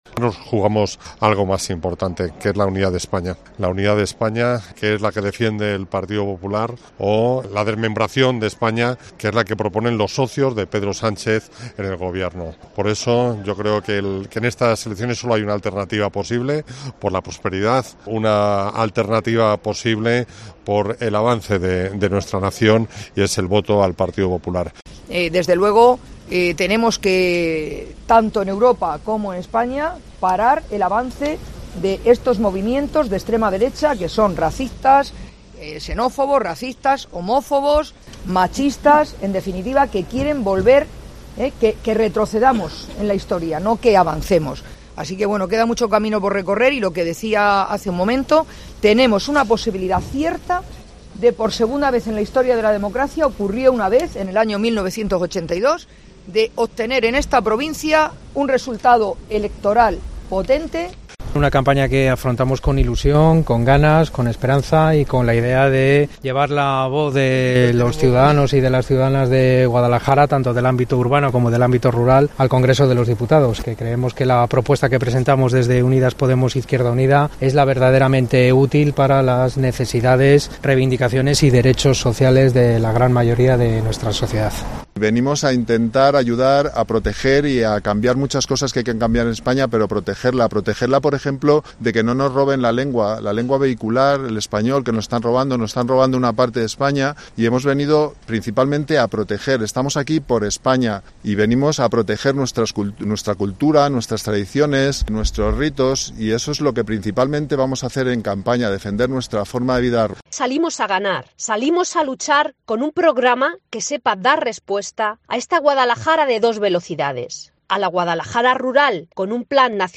Ha comenzado oficialmente la campaña electoral con la tradicional pegada de carteles en Guadalajara